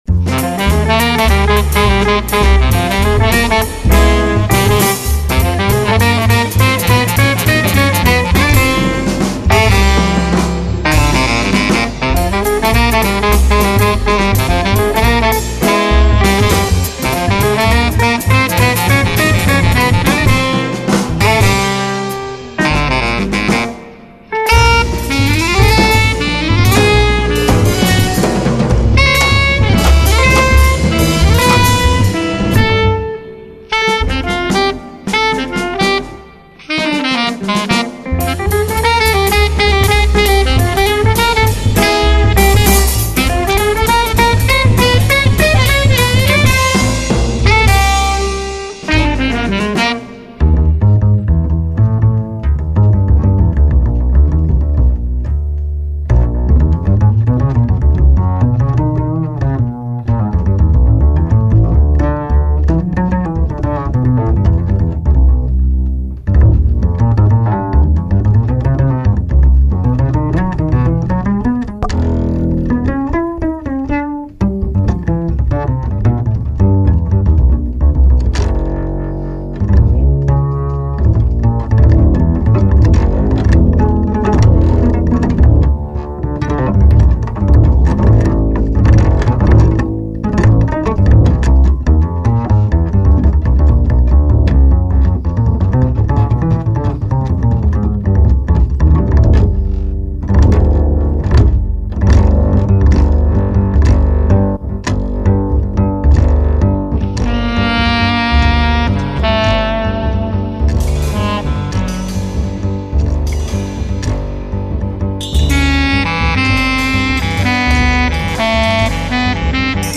alto sax, bass clarinet
guitar
bass
drums